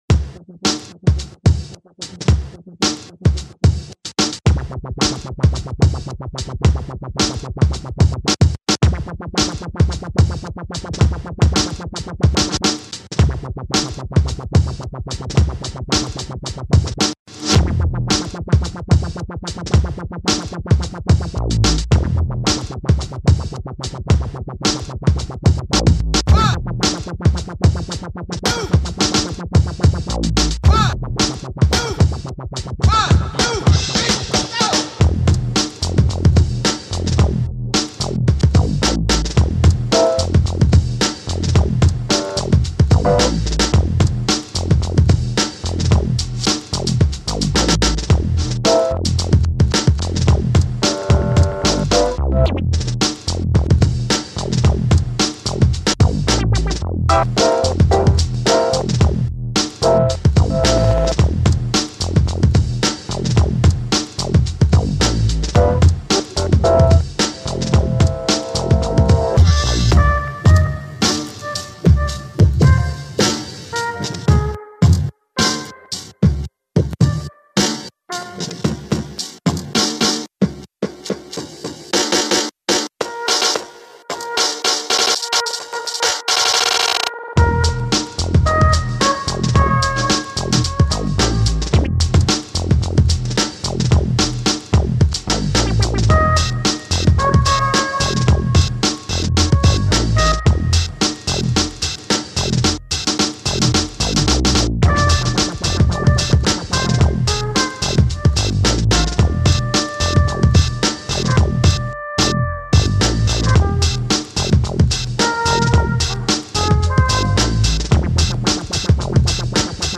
dance/electronic
House
UK-garage
Breaks & beats